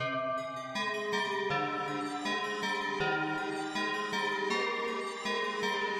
Tag: 160 bpm Trap Loops Synth Loops 1.01 MB wav Key : B